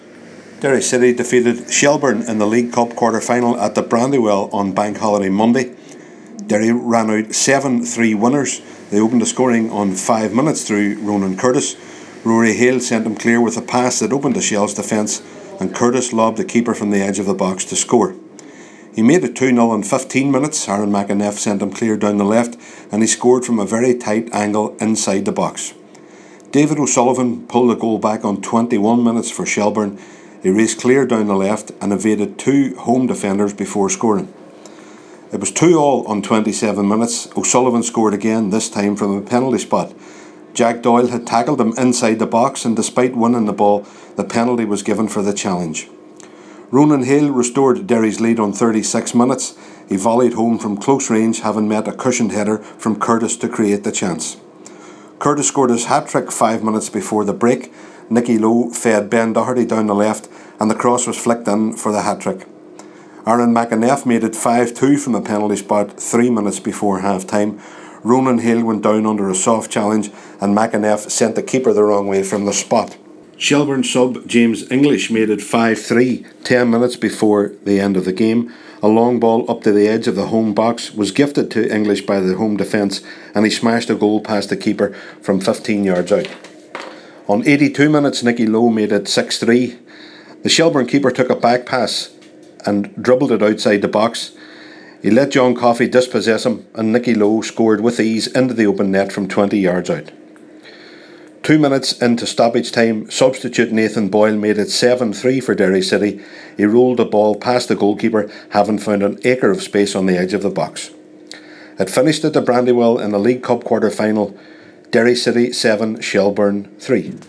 reports from The Brandywell…